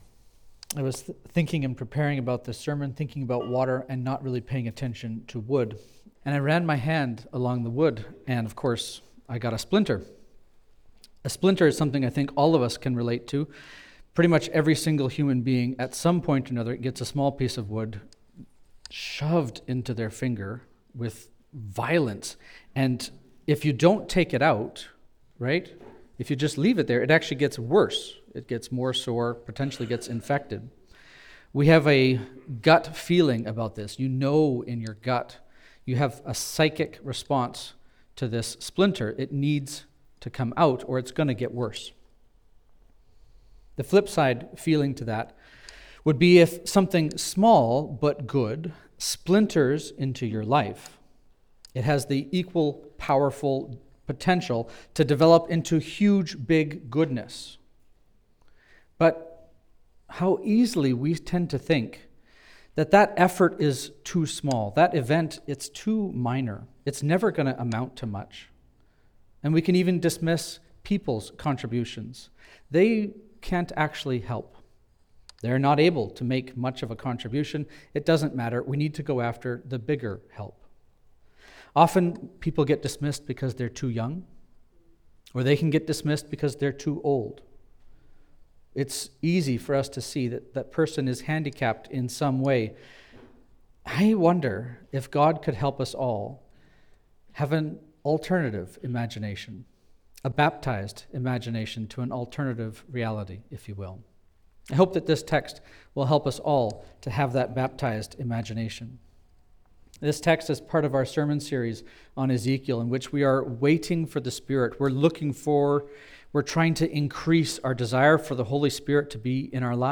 Sermons | Living Hope Church